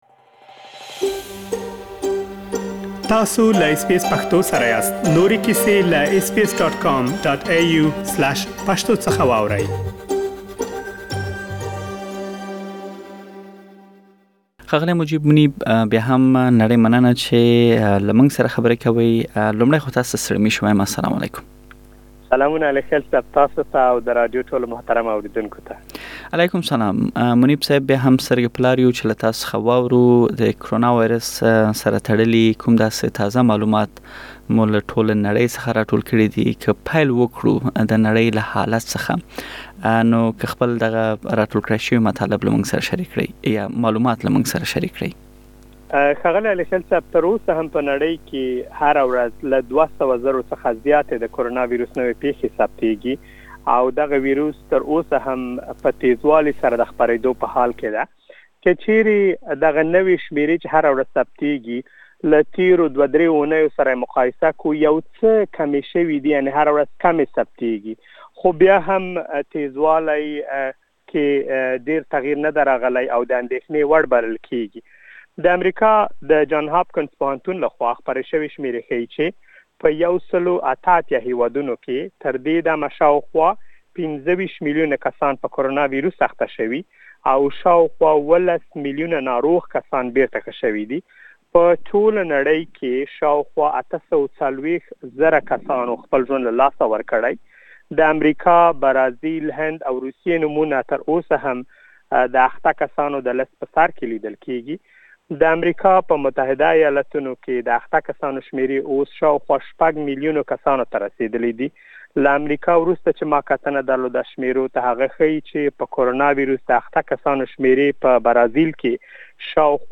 د اسټراليا، افغانستان، پاکستان او نړۍ تازه حال په رپوټ کې واورئ.